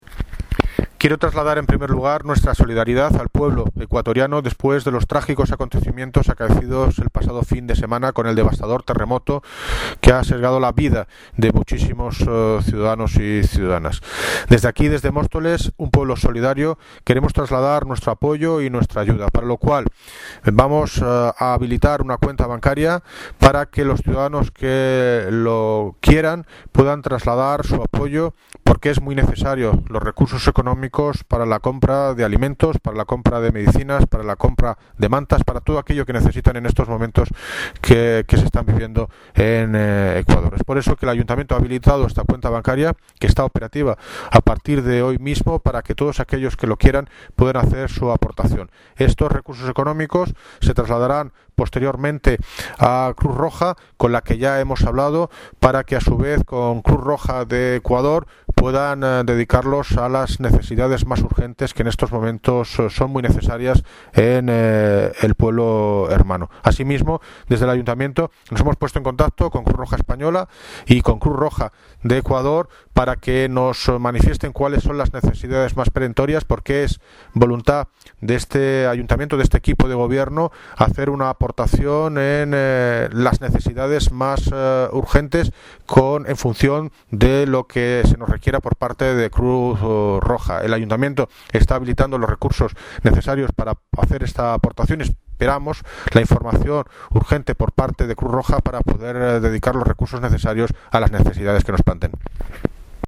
Audio - David Lucas (Alcalde de Móstoles) Sobre ayuda ecuador